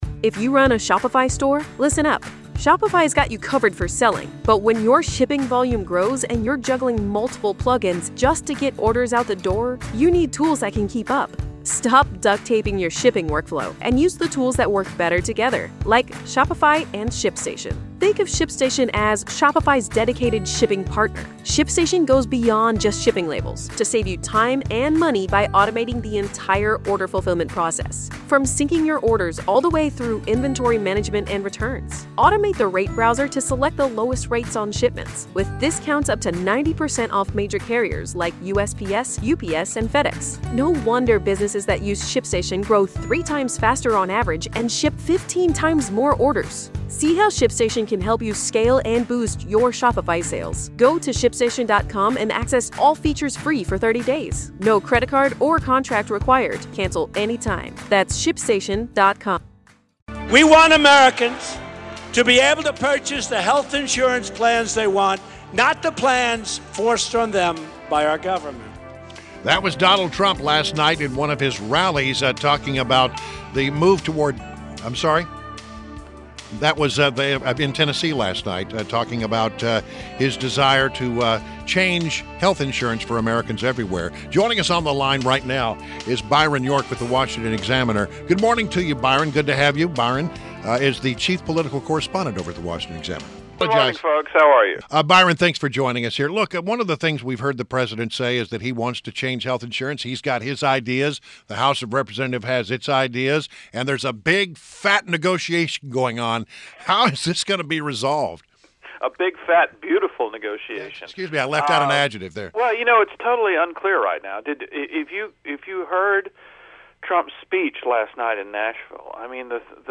WMAL Interview - BYRON YORK - 03.16.17